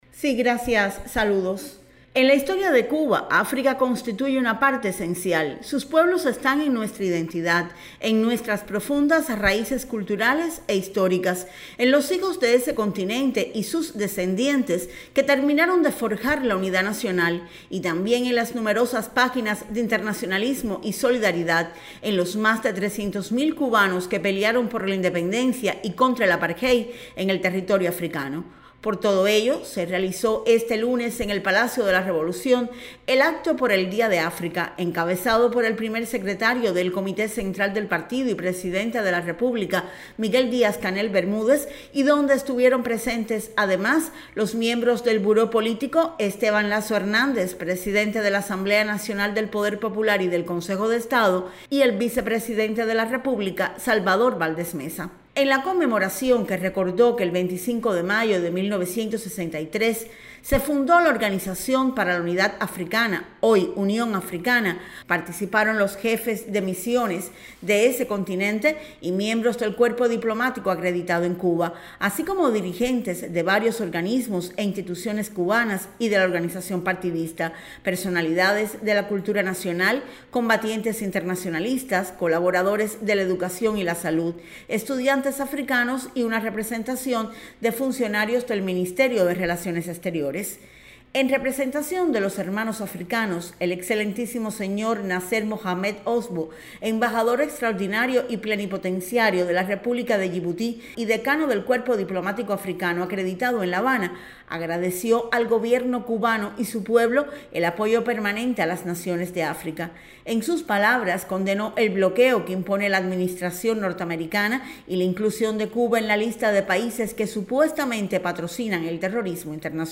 Con la presencia del Primer Secretario del Comité Central del Partido Comunista y Presidente de la República de Cuba, Miguel Díaz-Canel Bermúdez, tuvo lugar en la tarde de este lunes, desde el Salón Portocarrero del Palacio de la Revolución, el Acto Central por el Día de África